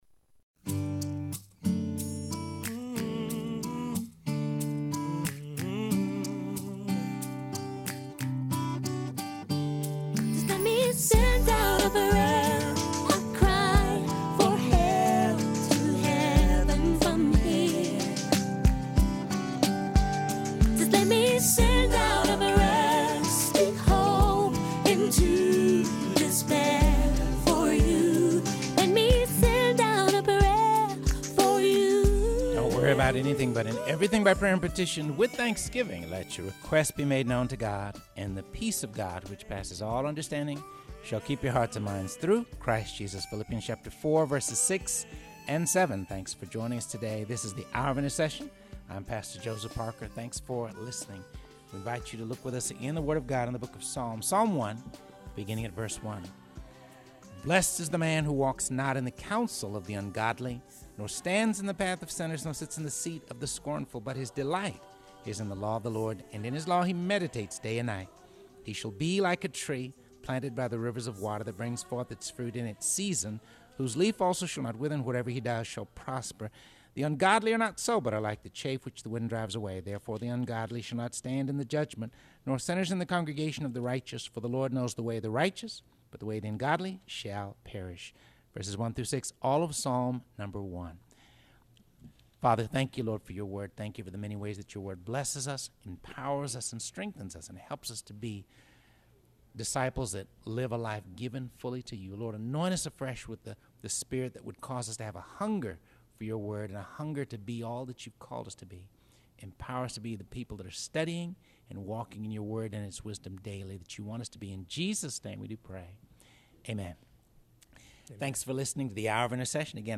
broadcasts LIVE from The Heart Cry for Revival Fusion Conference at the Billy Graham Evangelistic Center in Asheville, North Carolina